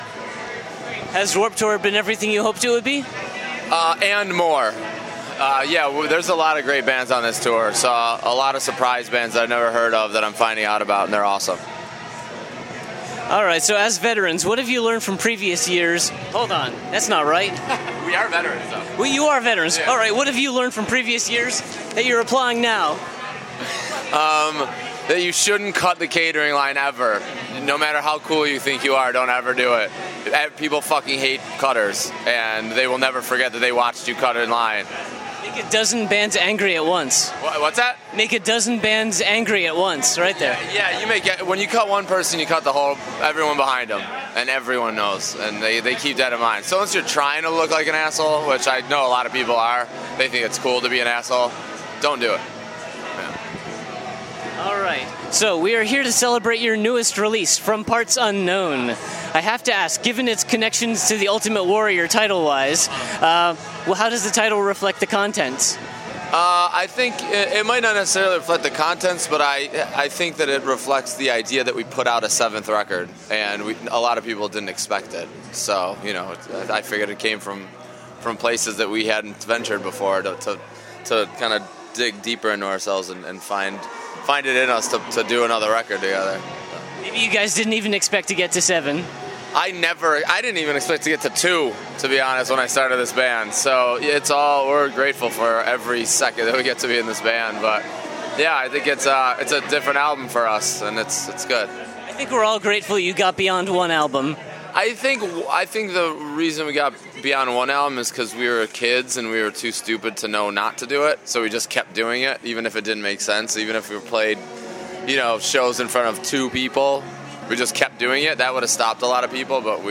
Exclusive: Every Time I Die Interview
We had the chance to sit down with Keith Buckley of Every Time I Die at the Scranton, PA stop of the Vans Warped Tour. Together we talked about the band’s new album From Parts Unknown, touring, movie plot holes, info on an upcoming side project, his hatred of Collective Soul and Guns N’ Roses, and being that this year is the tour’s 20th anniversary, memories of his first Warped Tour experience.
49-interview-every-time-i-die.mp3